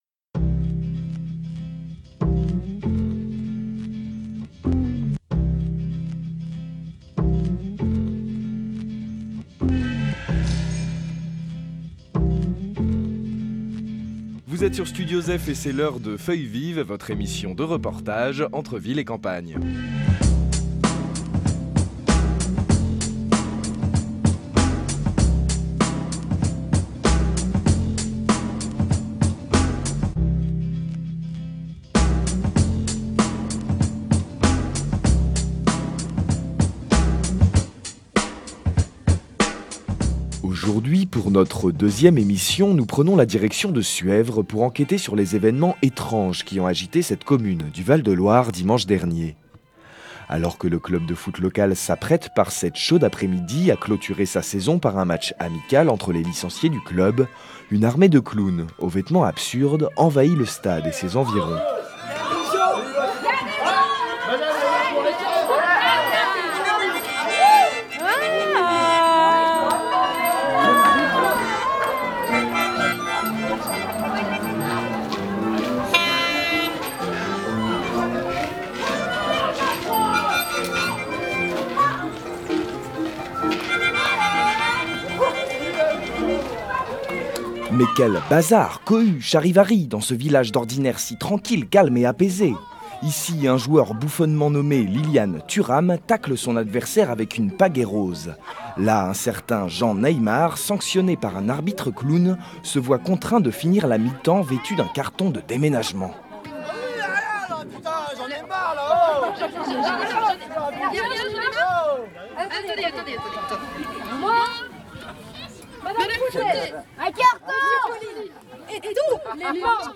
Dans cette deuxième émission de Feuilles vives, nous nous rendons du côté de Suèvres pour un reportage sur la compagnie de clown et de spectacle vivant Jean et Faustin.